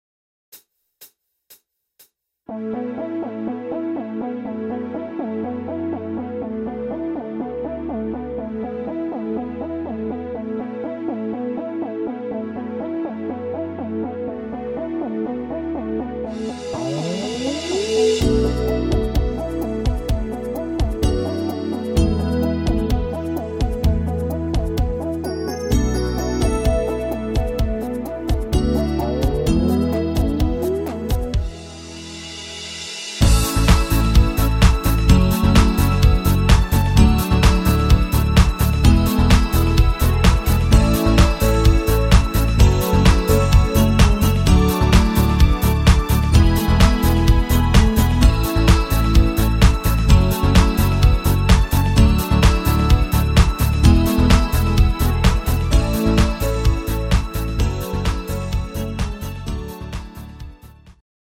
Rhythmus  Disco
Art  Englisch, Pop